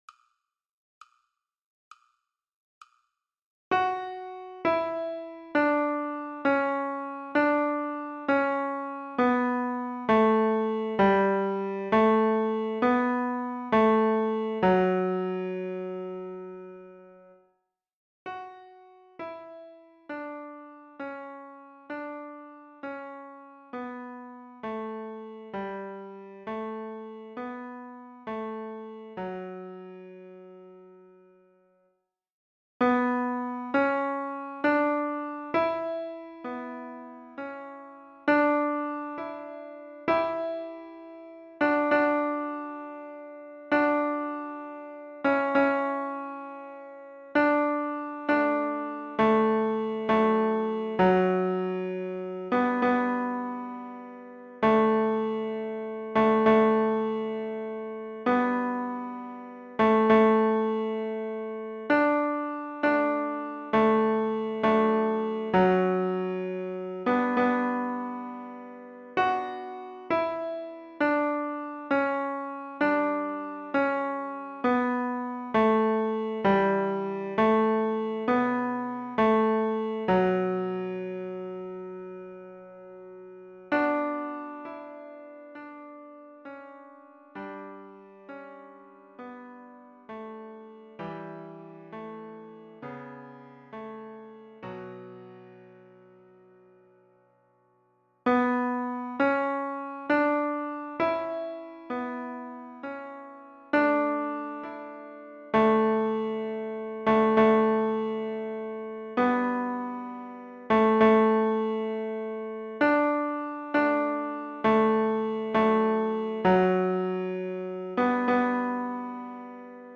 GIvs5LRSpMp_juste-un-peu-de-silence-chorale-Ténor.mp3